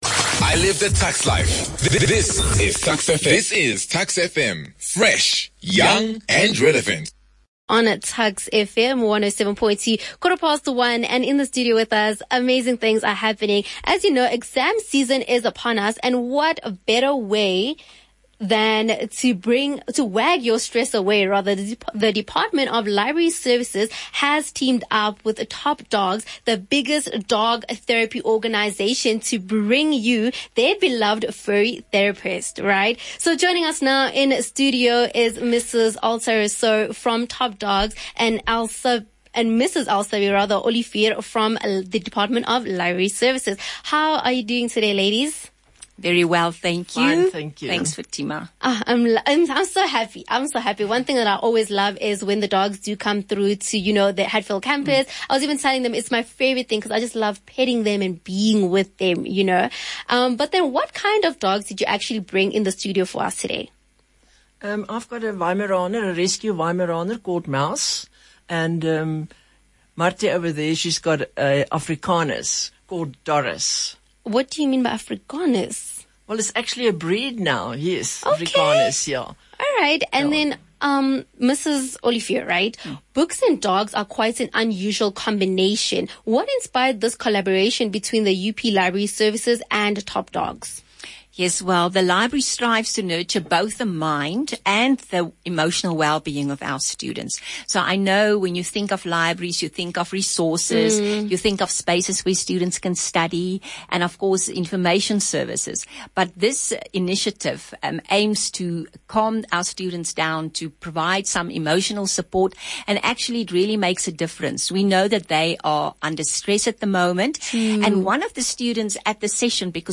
Top dog interview.mp3